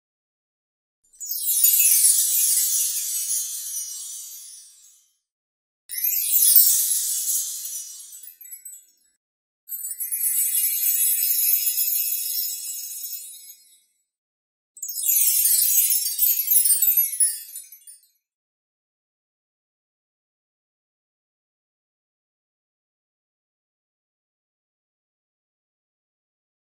دانلود آهنگ چوب جادو و شعبده بازی 1 از افکت صوتی اشیاء
جلوه های صوتی
دانلود صدای چوب جادو و شعبده بازی 1 از ساعد نیوز با لینک مستقیم و کیفیت بالا